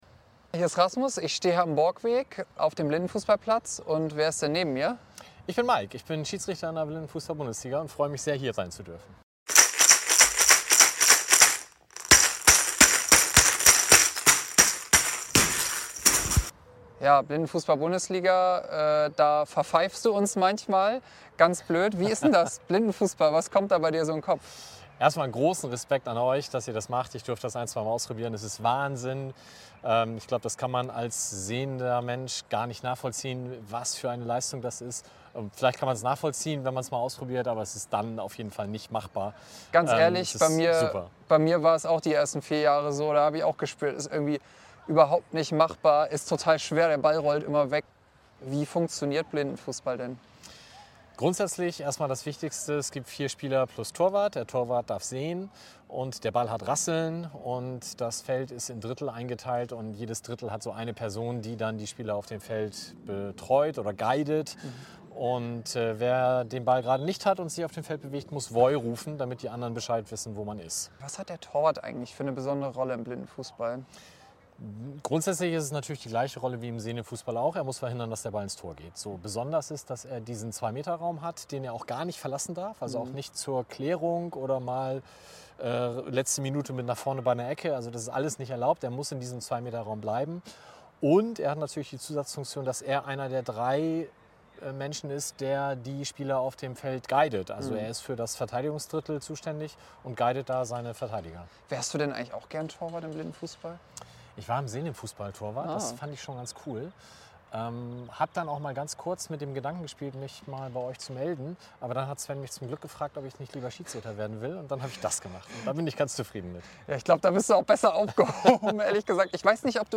Spieler und Schiedsrichter direkt vom Spielfeldrand.